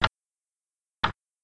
sounds_clock_tick.ogg